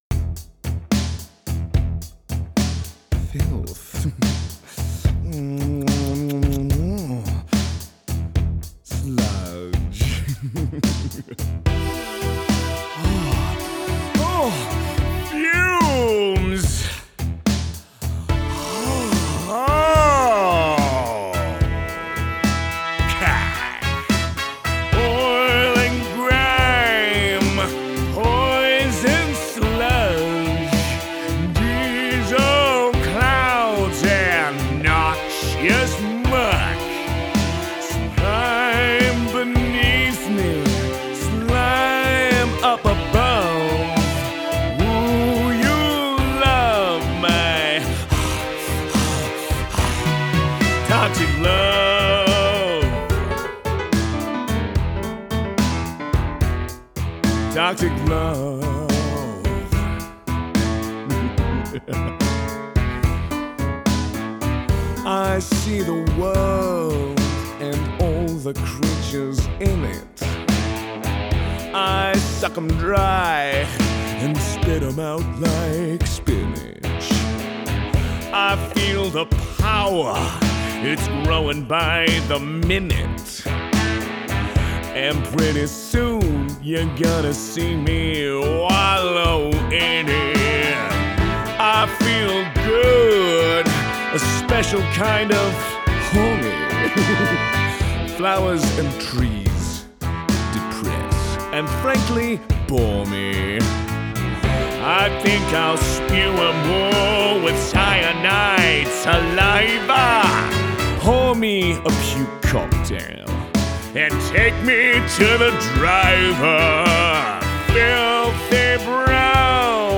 Singing Showreel
Male